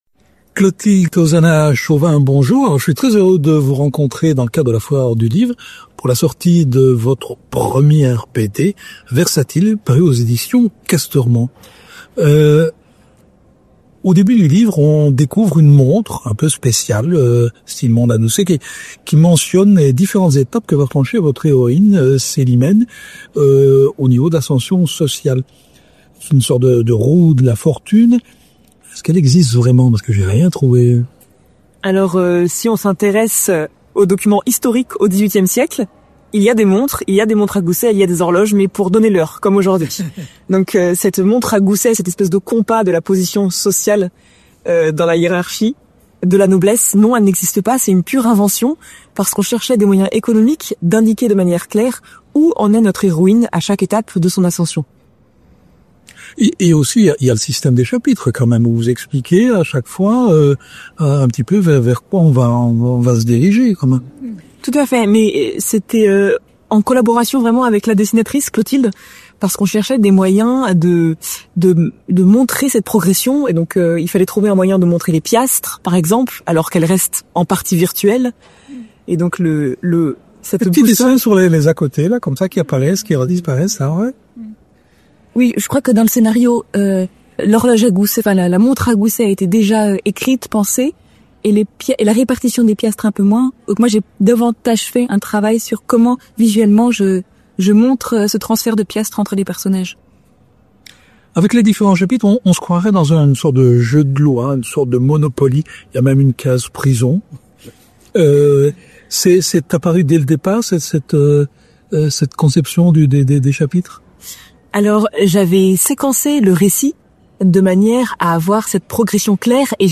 Rencontre avec les autrices. On y parle d’Icare, de montre gousset, de catin, de diamant, du XVIIIe siècle, de marivaudage, de calligraphie, d’amitié… et d’Amour!